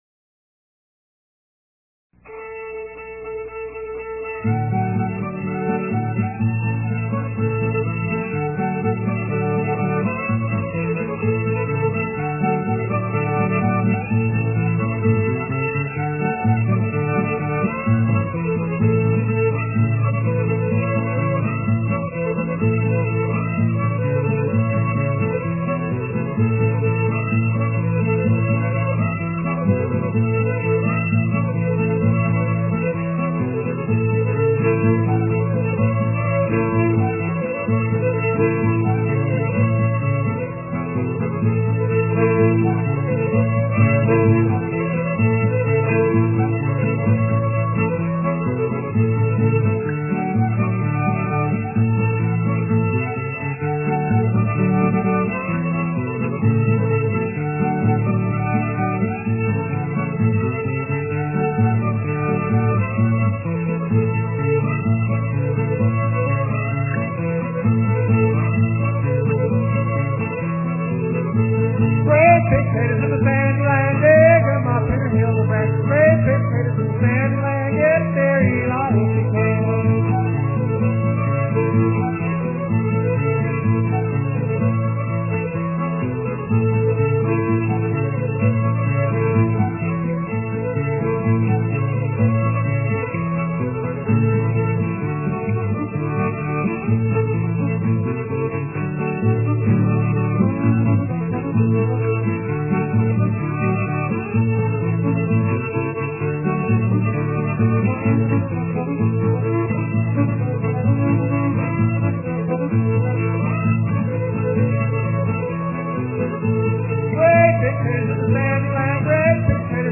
With their crystal-powered acoustic instruments